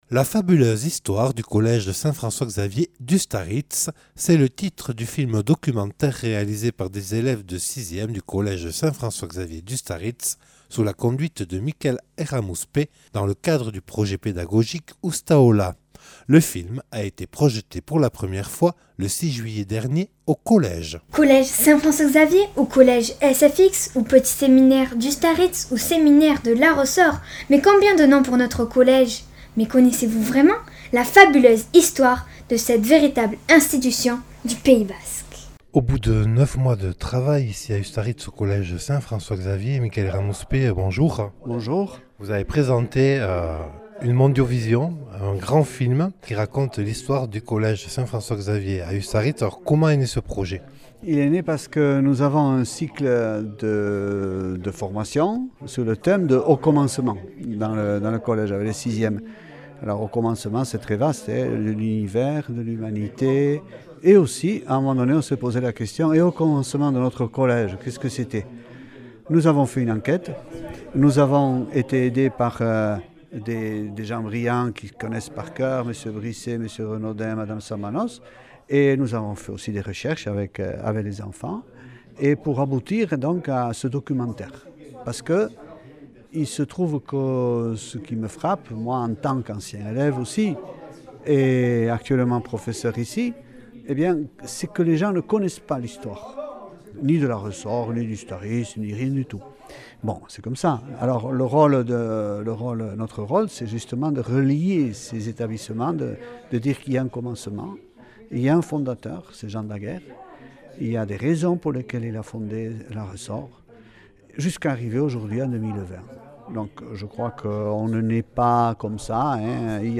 Le film a été projeté pour la première fois le 6 juillet dernier à Ustaritz. A cette occasion, nous avons pu recueillir plusieurs témoignages.
Accueil \ Emissions \ Infos \ Interviews et reportages \ « La fabuleuse histoire du collège Saint François-Xavier d’Ustaritz (...)